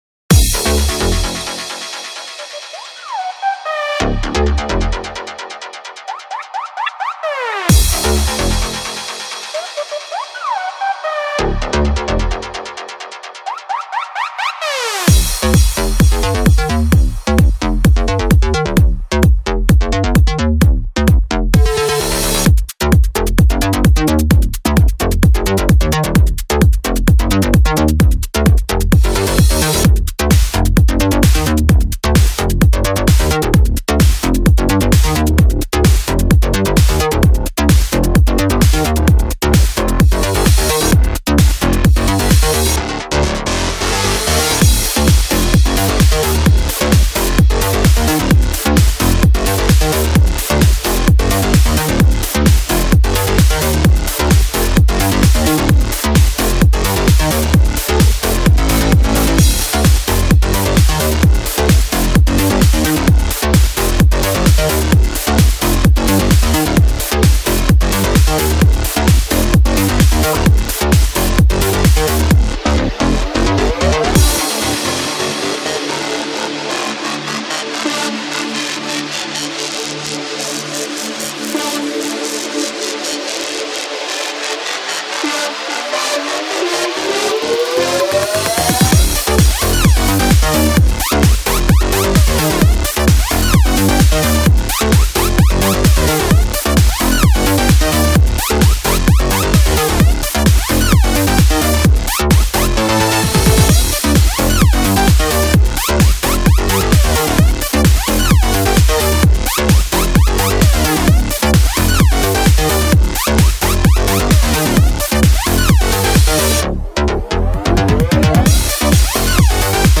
Club Music